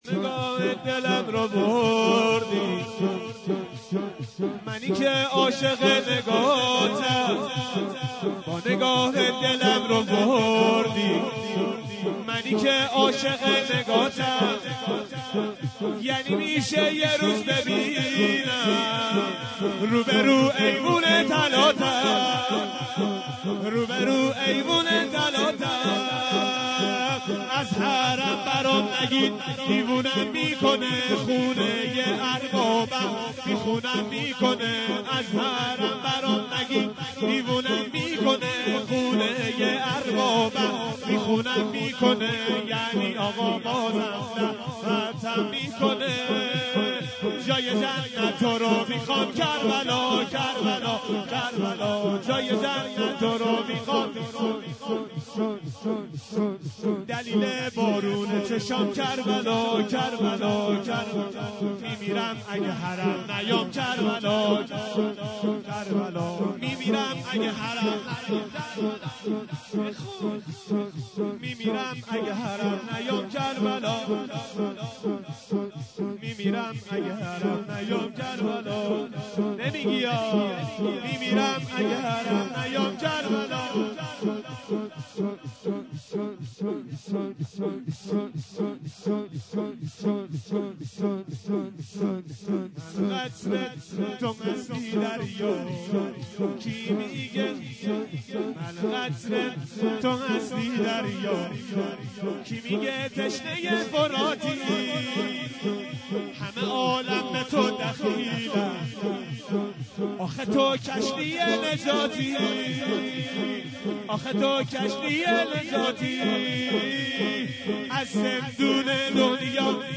در ادامه صوت امداحی این مراسم منتشر می شود.